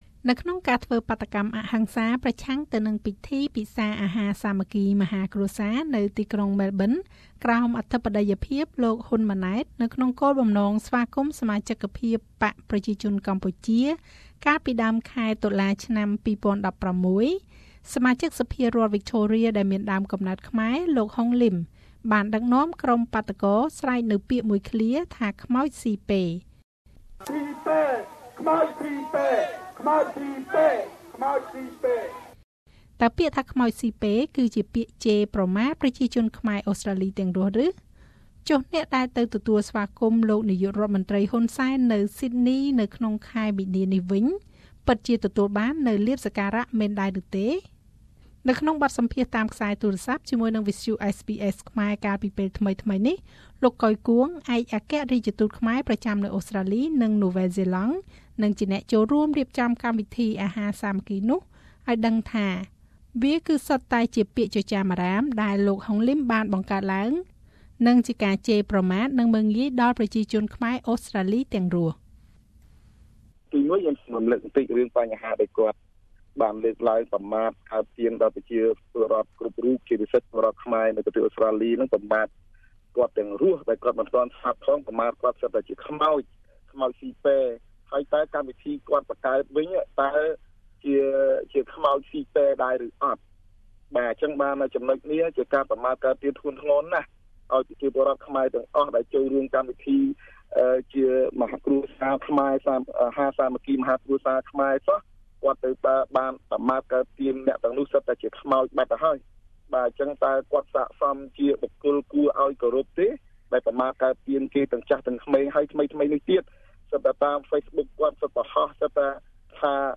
សូមស្តាប់ការបកស្រាយនិងការអះអាងរៀងៗខ្លួនរបស់លោក កុយ គួង ឯកអគ្គរាជទូតខ្មែរប្រចាំនៅប្រទេសអូស្រ្តាលីនិងនូវ៉ែលហ្សេឡង់ និងលោក ហុង លីម តំណាងរាស្រ្តដើមកំណើតខ្មែរប្រចាំនៅតំបន់ក្លារីដានៃរដ្ឋវិចថូរៀ ប្រទេសអូស្ត្រាលី៕